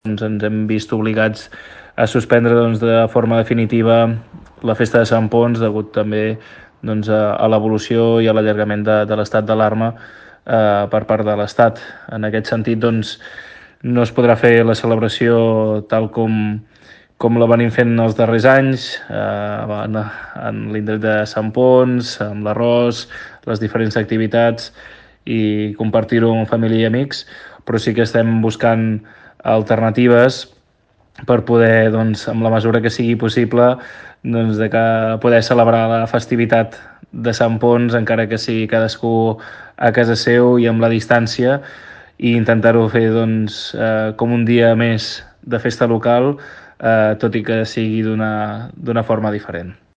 Ho explica el regidor de festes, Pau Megias.